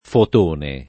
fotone
fotone [ fot 1 ne ]